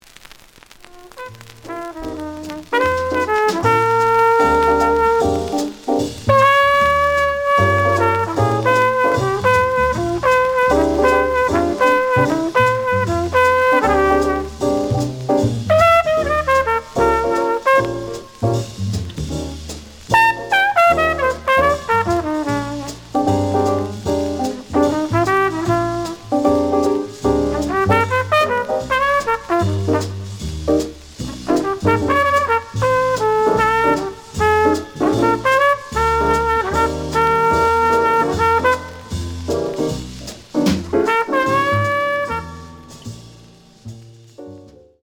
The audio sample is recorded from the actual item.
●Genre: Bop